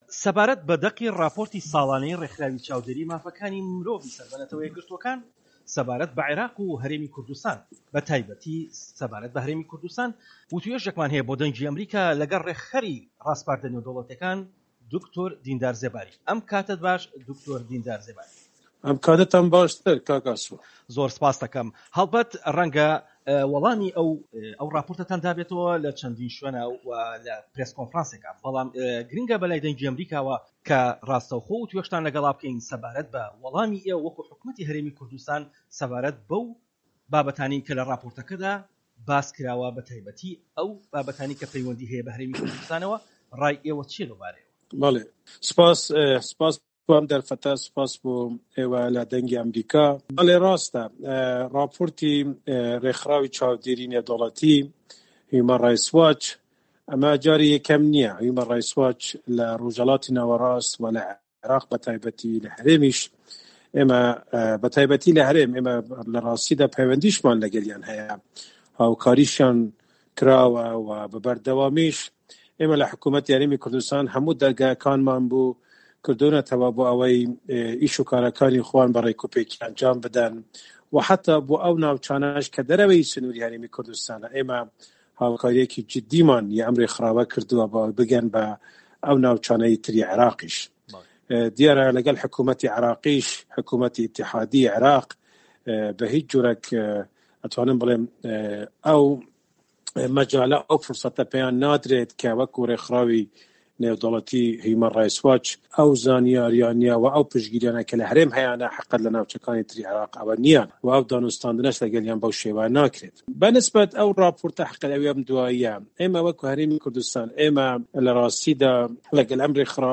وتووێژ لەگەڵ دیندار زێباری